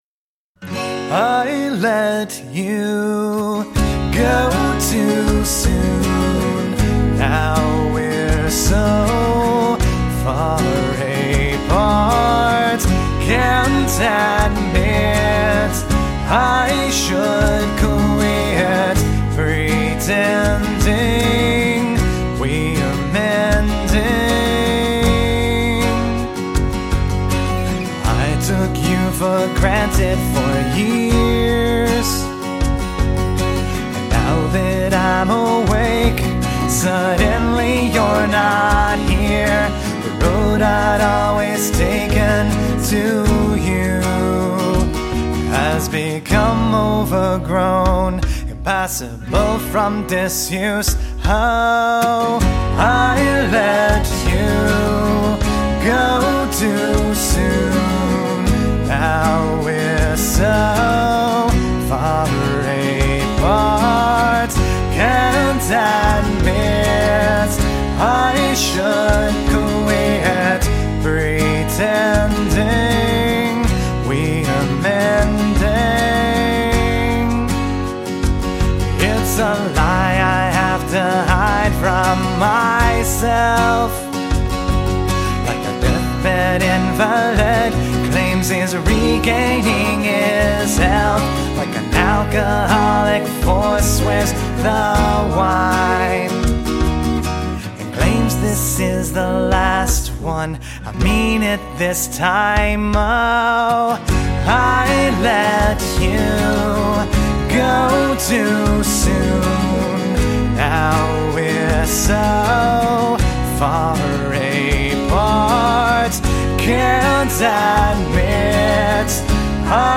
The folk duo
Melodic, acoustic, harmonic…and therapeutic.